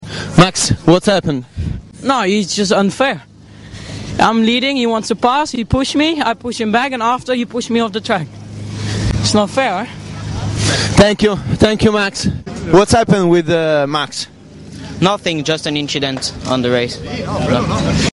👀🤫 Little bit of Simracing fun 🚀🤘 Game: Automobilista 2, online Track: Road America Car: Porsche 911 R GT3 .